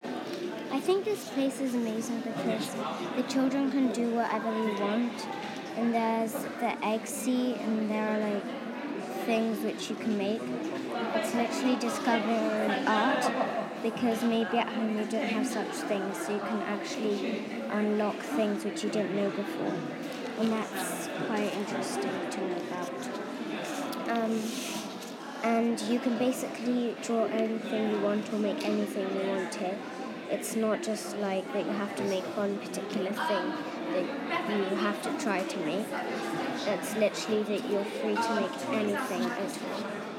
IT'S A GREAT PLACE (Audio Description of space and setting)